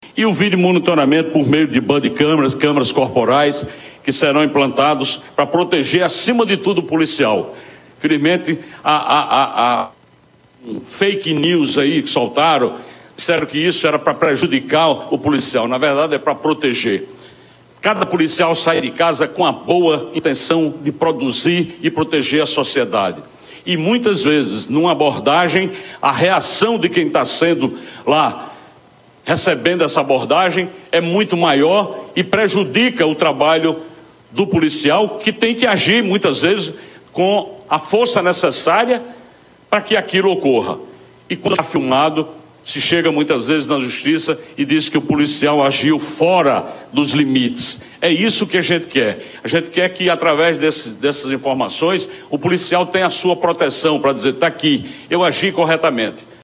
“O videomonitoramento por meio de câmeras, câmeras corporais que serão implantados para proteger, acima de tudo, o policial. Cada policial sai de casa com a boa intenção de produzir e proteger a sociedade. Muitas vezes, numa abordagem, a reação de quem está recebendo essa abordagem é muito maior e prejudica o trabalho do policial”, disse na coletiva.